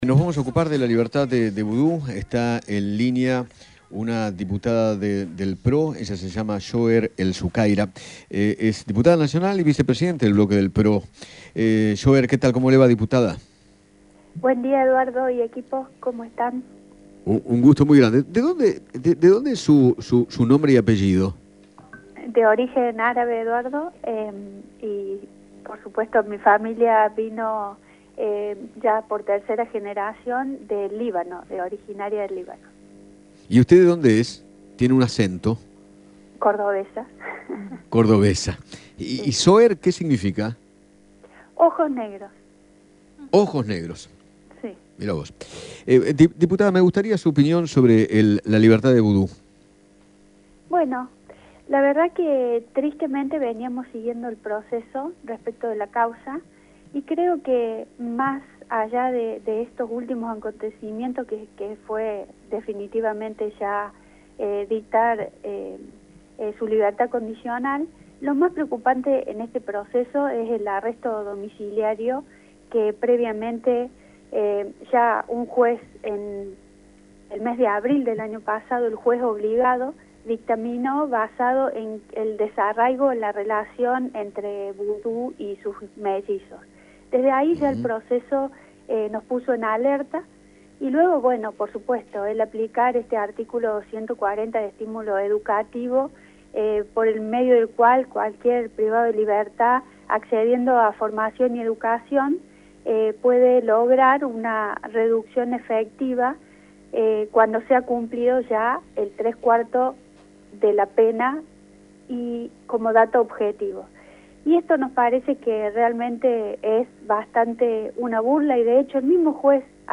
Soher El Sukaira, diputada nacional, conversó con Eduardo Feinmann  acerca de la libertad condicional de Amado Boudou y expresó que se ponga “bajo la lupa la presión que este Gobierno viene ejerciendo sobre el Poder Judicial”.